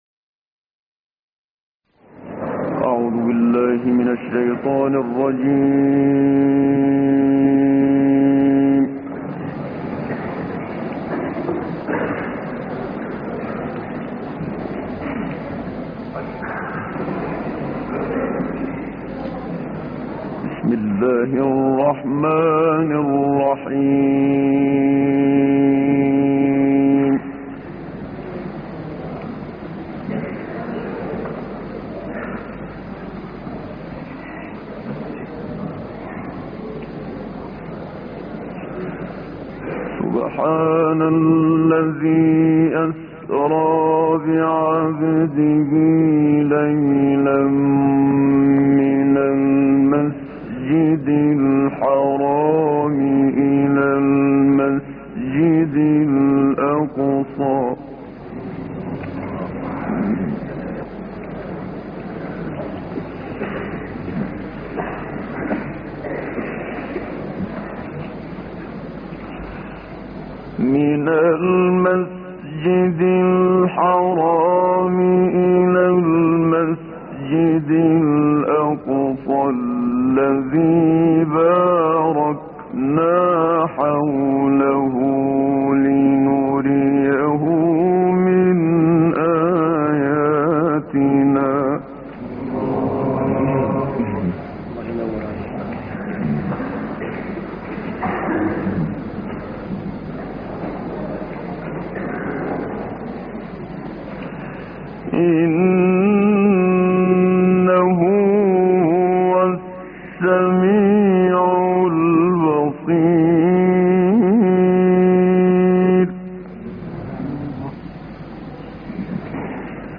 عنوان المادة 017 الإسراء 1-14 تلاوات نادرة بصوت الشيخ محمد صديق المنشاوي تاريخ التحميل السبت 11 نوفمبر 2023 مـ حجم المادة 12.20 ميجا بايت عدد الزيارات 99 زيارة عدد مرات الحفظ 60 مرة إستماع المادة حفظ المادة اضف تعليقك أرسل لصديق